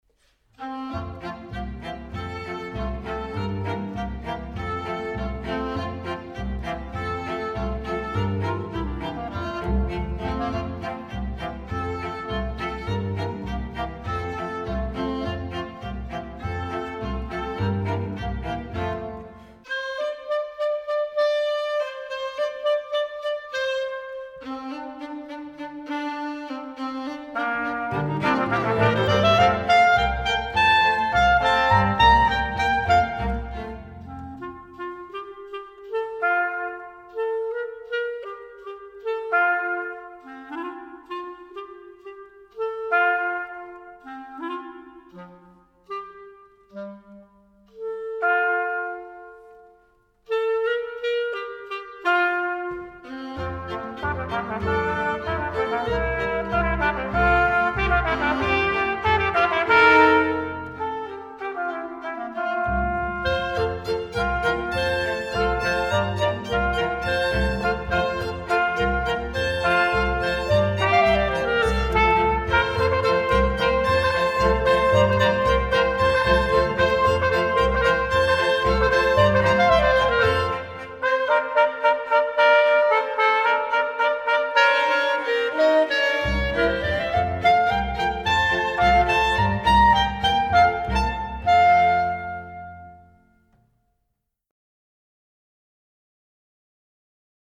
The music resulted in a small suite in four movements, one for each of the main parts of the play.
Violin
Trumpet
Clarinet
Cello
Contrabass